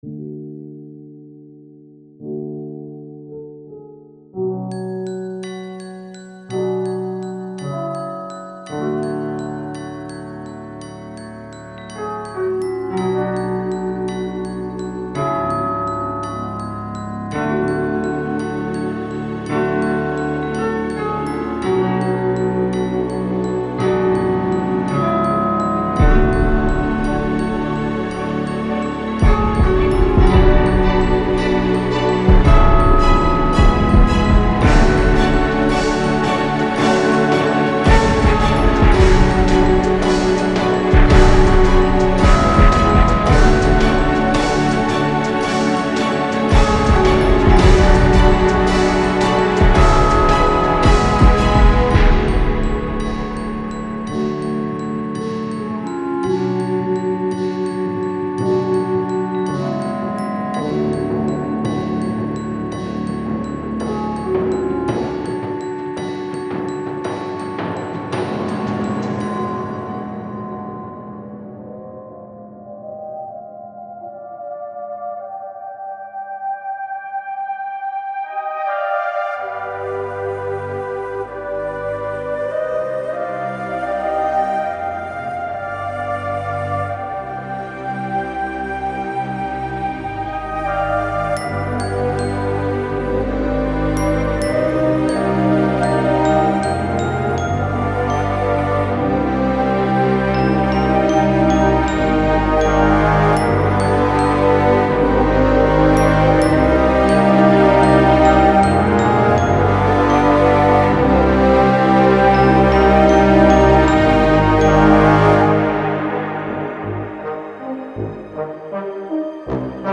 从钢琴到弦乐，竖琴，牛角，长笛等等！
这种免版税的音色库具有各种各样的管弦乐器：从钢琴到弦乐器，竖琴，长笛，吉他，号角和celesta。
子冲击力和打击乐声也包括在内。